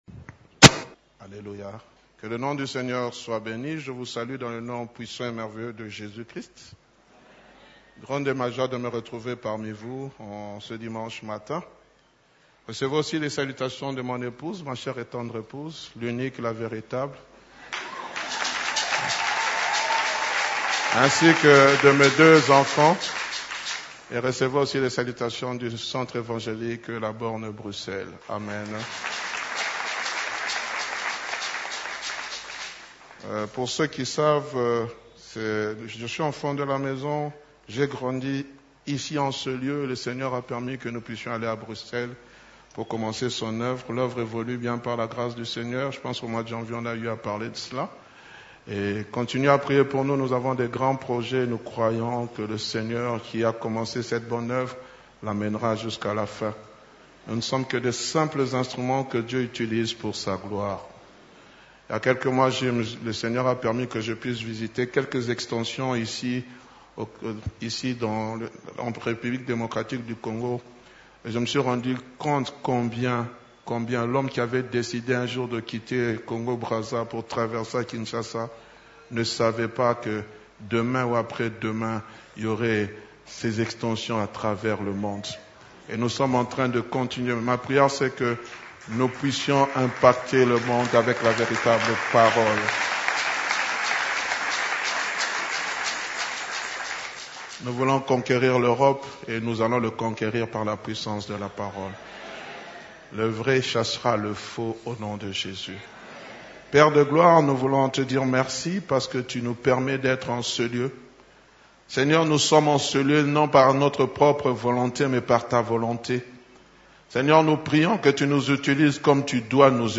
CEF la Borne, Culte du dimanche, Prédication : Judas, un vêtement nouveau de louange